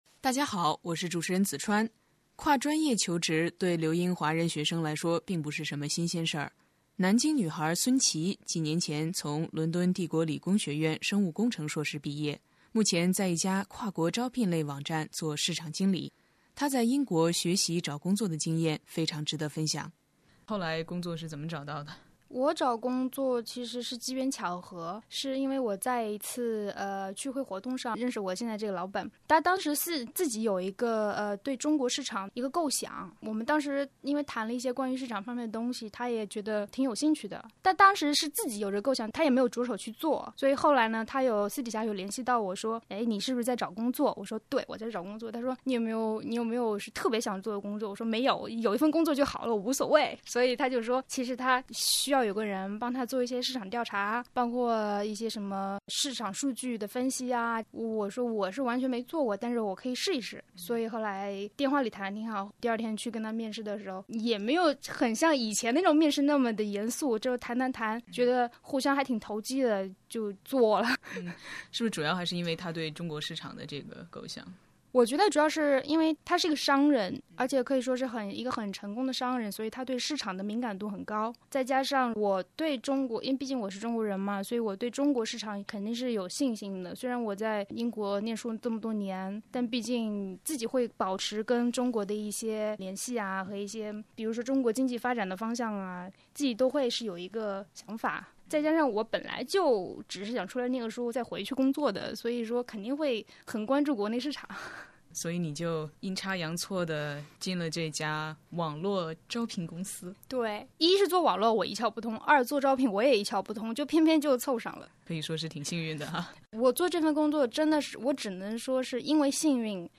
《留英访谈》专访学生、教师、家长、教育专家，介绍留学英国经验、权威问题解答、实用留学信息、探讨热点留学话题。